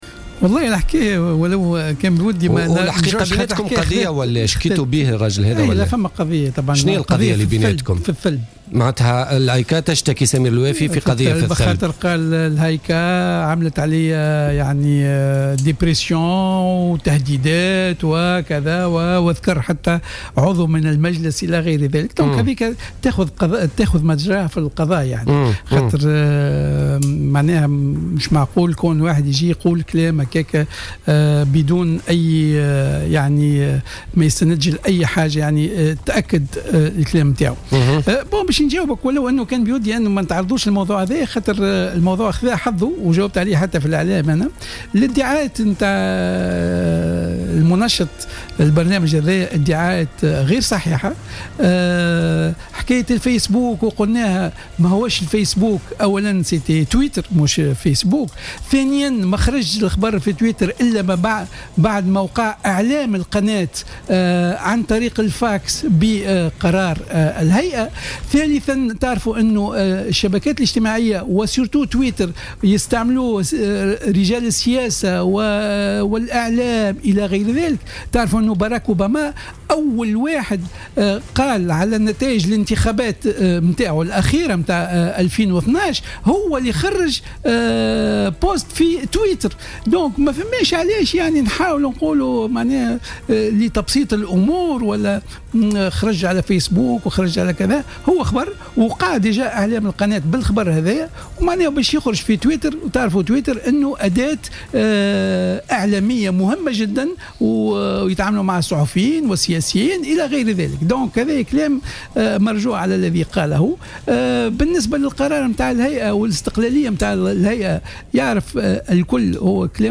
قال رئيس الهيئة العليا المستقلة للاتصال السمعي البصري،النوري اللجمي،ضيف برنامج "بوليتيكا" اليوم الأربعاء إن الهيئة رفعت قضية ضد الإعلامي سمير الوافي من أجل الثلب.